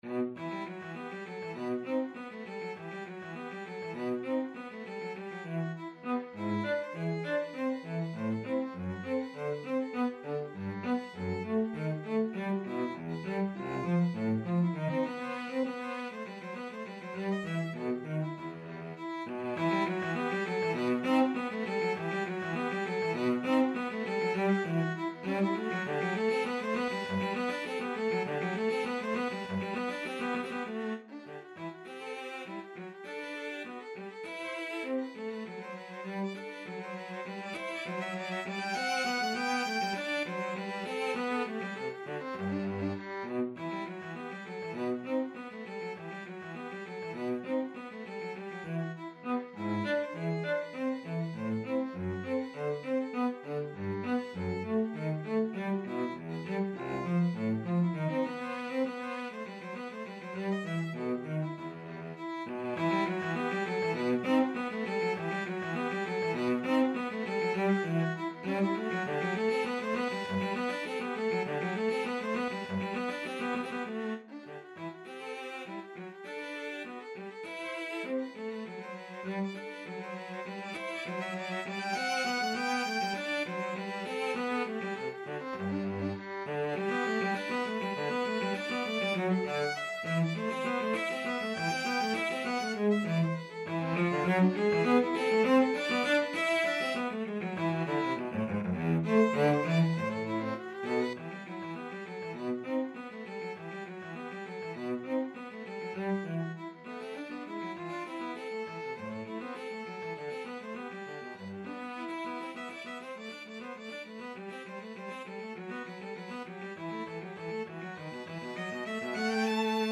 4/4 (View more 4/4 Music)
~ = 100 Allegro (View more music marked Allegro)
Classical (View more Classical Violin-Cello Duet Music)